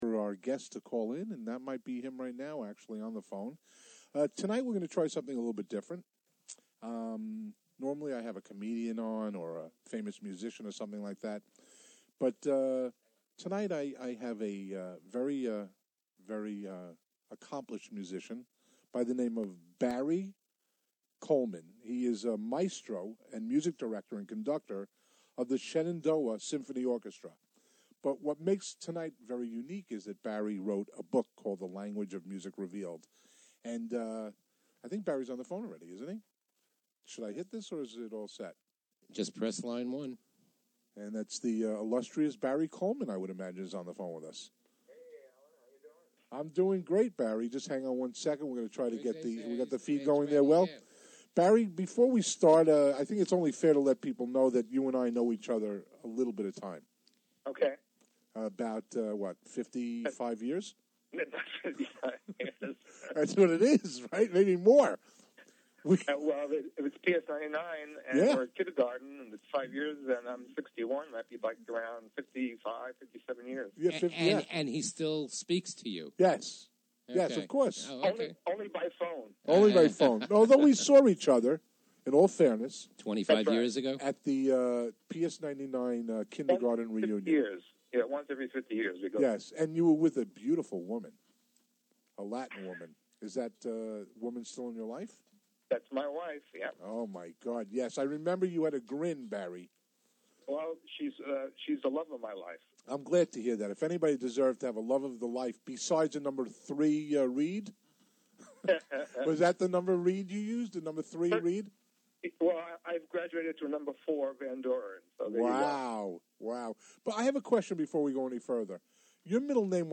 Press and Media Interviews